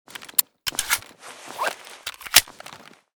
sig220_reload.ogg.bak